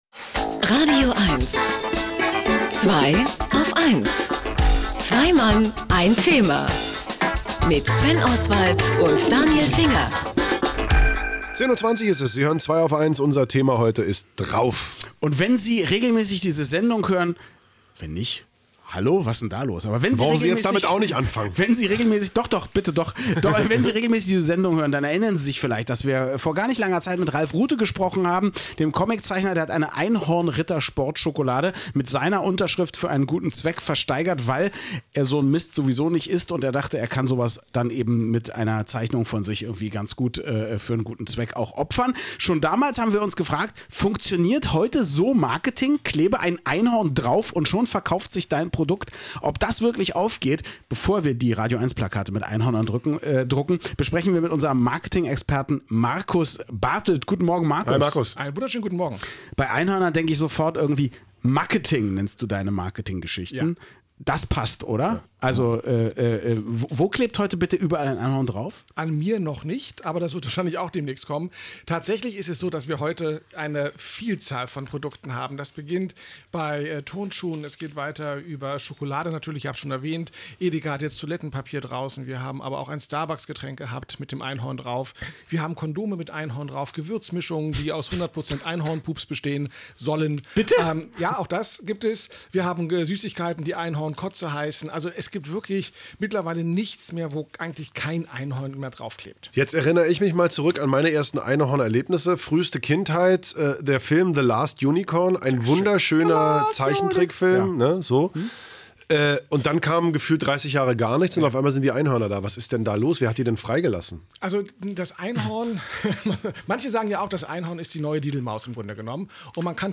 Es begab sich vor gar nicht einmal allzu langer Zeit, dass ein scheues, schüchternes Einhorn (moi) sich auf den Weg machte zu radioeins in das kalte Potsdam-Babelsberg, um sich dort den zwei wackeren Recken (die Herren von Zweiaufeins) zu stellen und ihnen ein wenig darüber zu erzählen, warum man heute nur ein Bild von einem Einhorn auf etwas raufpappen muss, um es erfolgreich zu verkaufen: